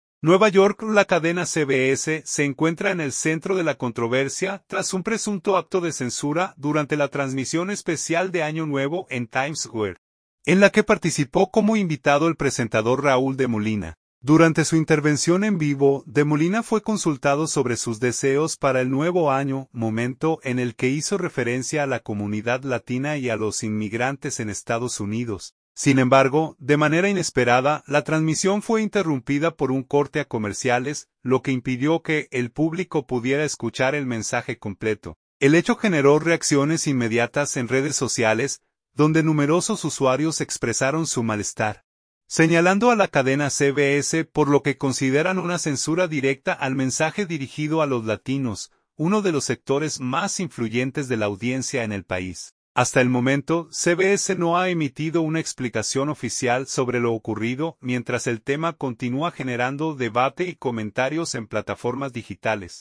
NUEVA YORK.– La cadena CBS se encuentra en el centro de la controversia tras un presunto acto de censura durante la transmisión especial de Año Nuevo en Times Square, en la que participó como invitado el presentador Raúl de Molina.
Sin embargo, de manera inesperada, la transmisión fue interrumpida por un corte a comerciales, lo que impidió que el público pudiera escuchar el mensaje completo.